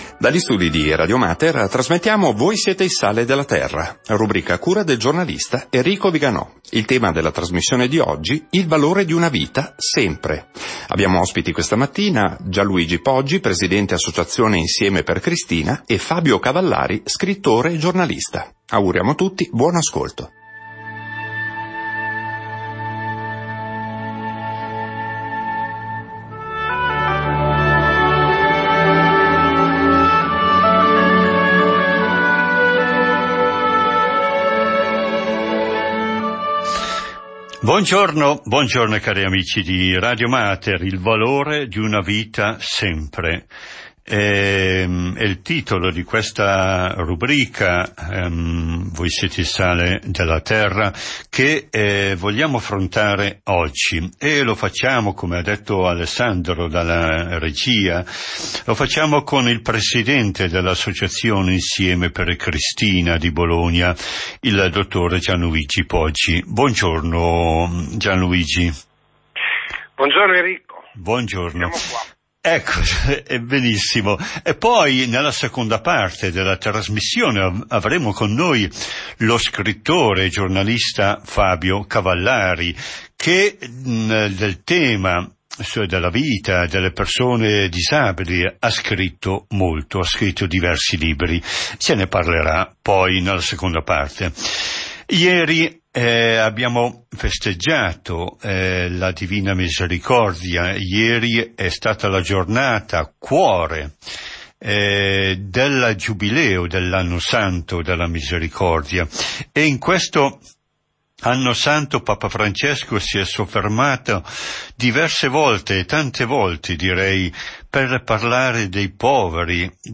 Audio – Radio Mater. Intervista